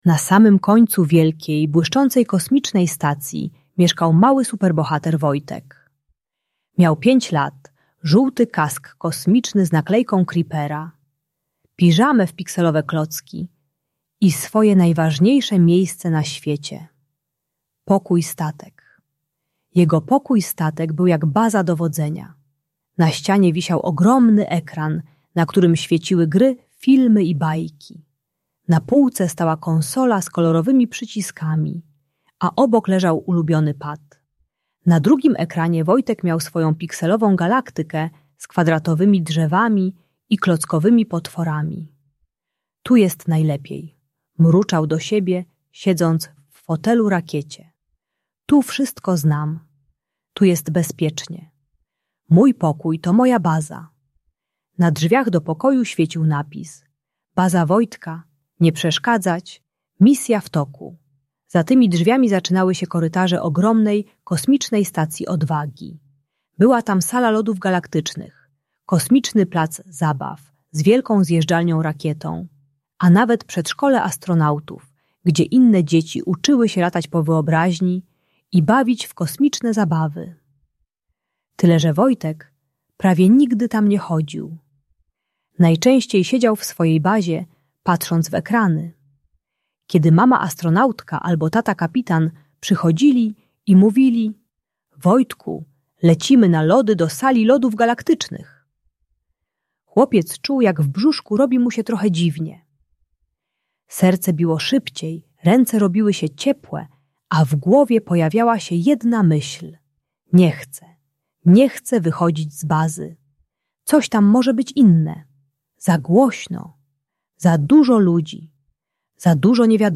Audiobajka uczy techniki "krok po kroku" - małych, bezpiecznych kroków poza strefę komfortu, z możliwością powrotu.